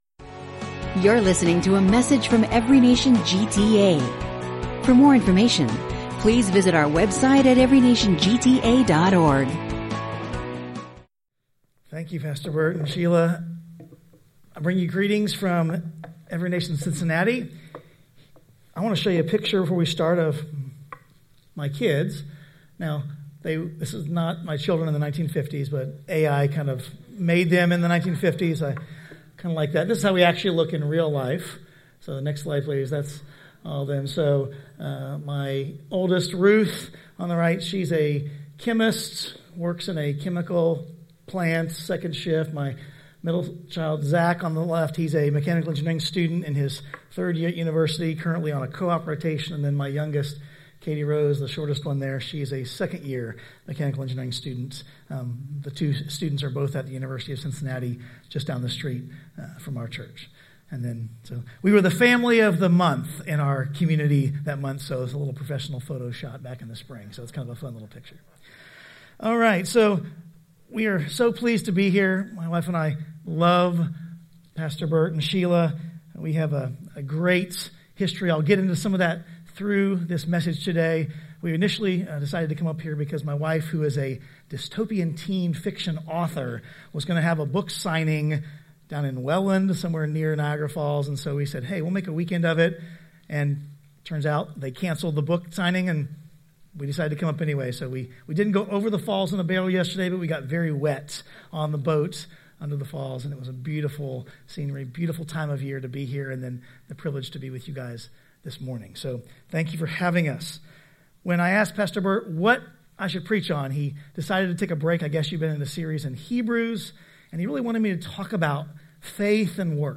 One-off messages